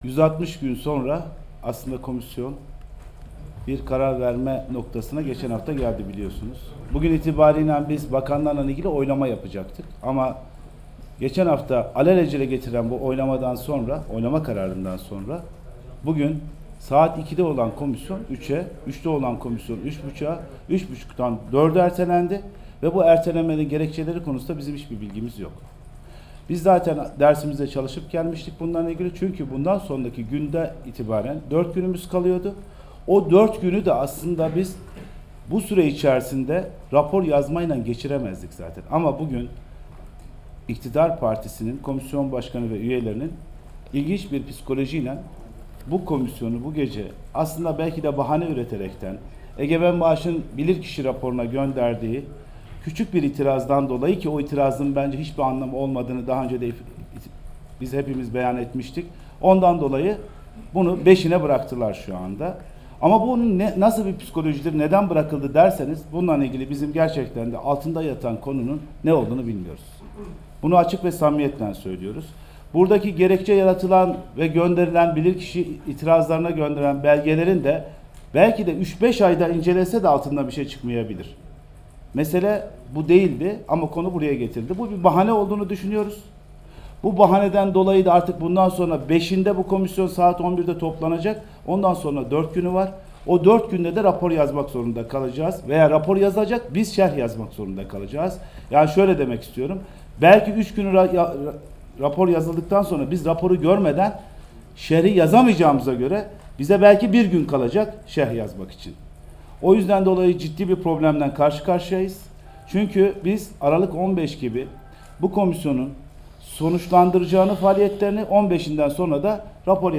CHP'li vekillerin basın toplantısı